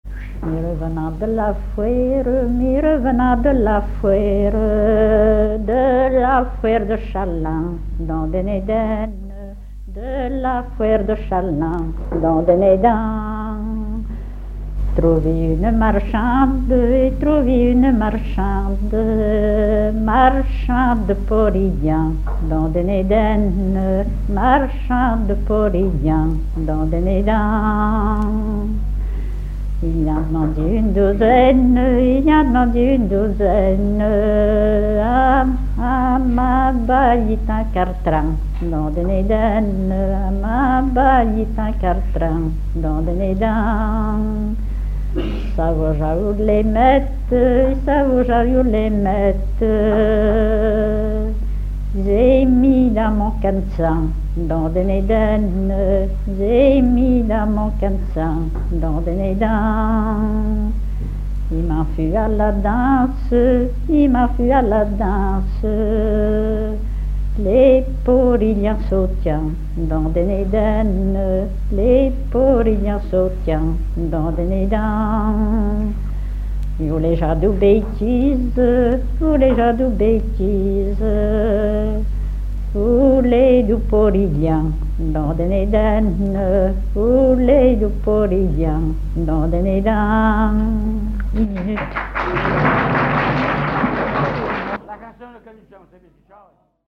Genre laisse
à la salle d'Orouët
Pièce musicale inédite